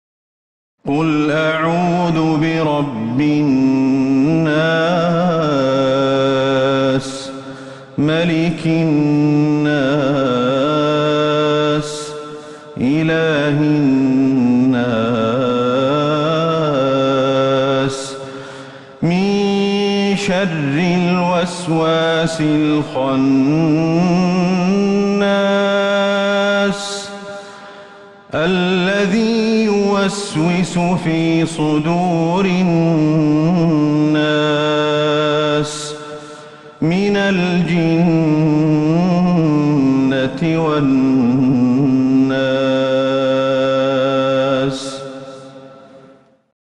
سورة الناس Surat An-Nas > المصحف المرتل من المسجد النبوي > المصحف - تلاوات الشيخ أحمد الحذيفي